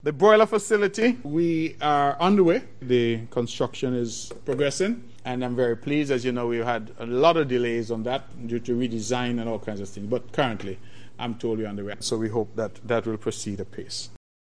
On February 24th, Premier the Hon. Mark Brantley provided an update on the progress of the broiler bird facility on Nevis: